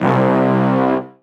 C_15_TrapBrass_SP_09.wav